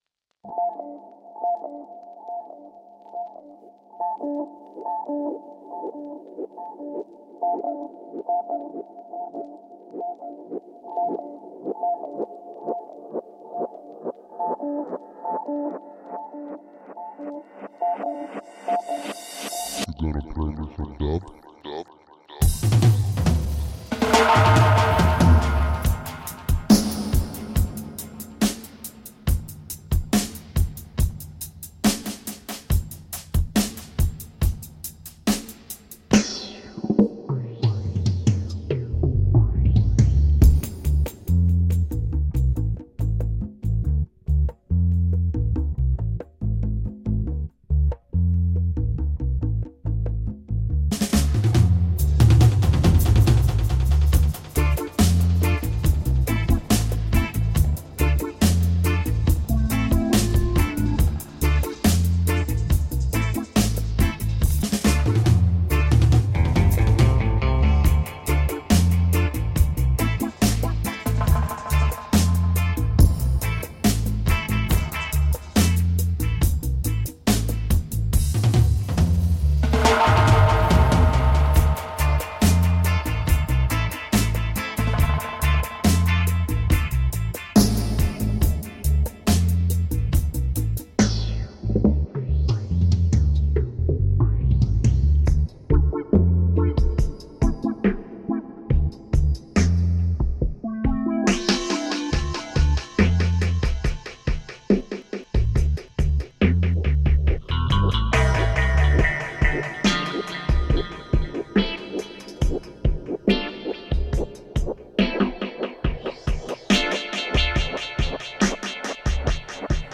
Super fine dub .
Tagged as: Electronica, World, Reggae, Dub